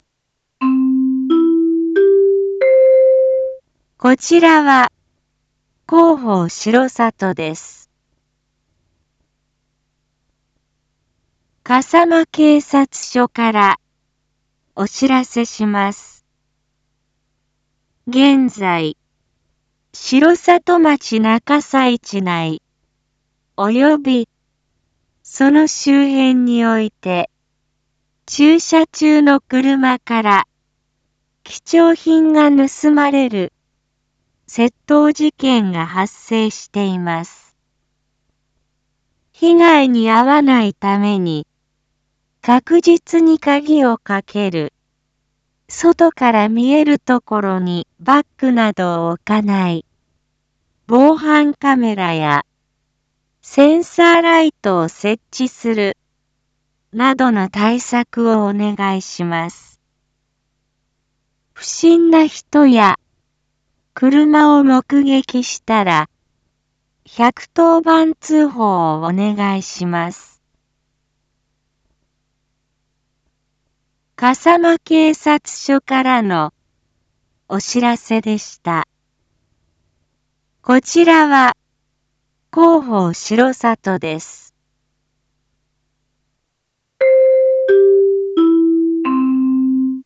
一般放送情報
Back Home 一般放送情報 音声放送 再生 一般放送情報 登録日時：2024-07-18 19:01:31 タイトル：笠間警察署からのお知らせ（窃盗事件） インフォメーション：こちらは広報しろさとです。